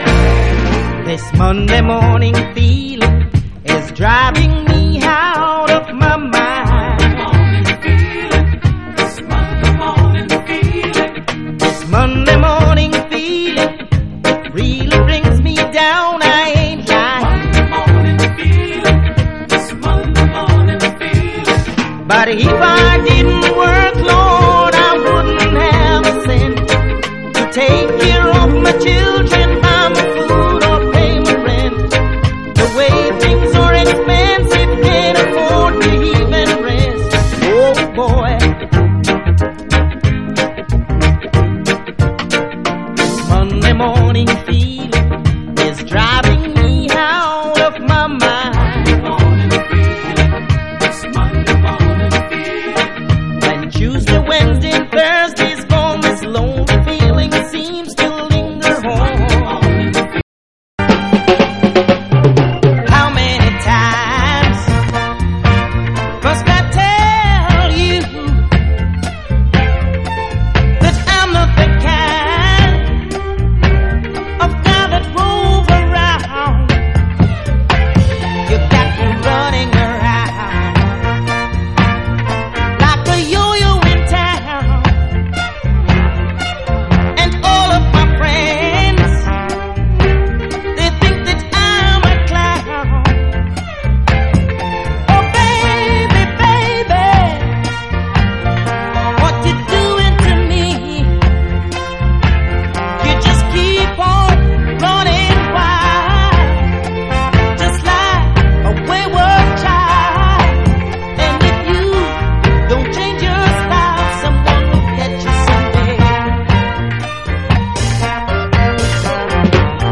政治性とスピリチュアルな響きが共存する70年代ルーツ音源を集成。